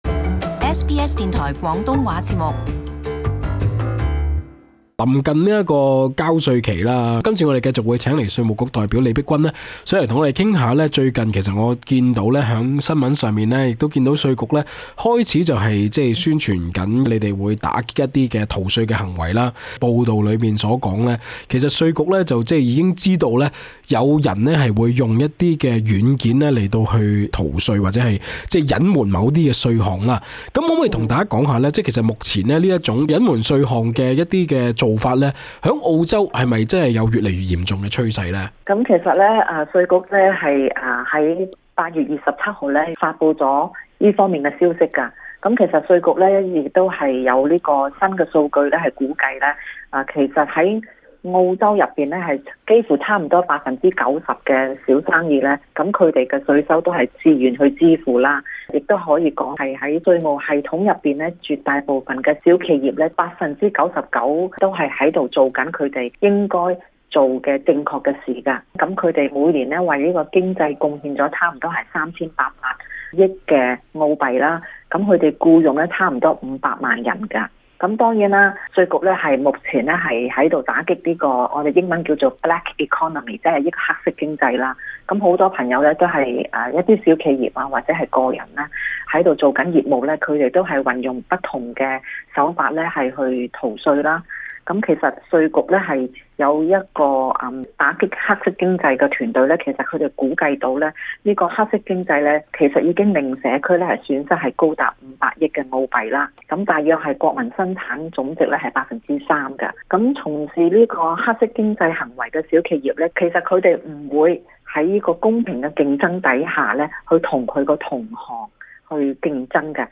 【社區專訪】ATO加強打擊企業利用電子軟件等方式逃稅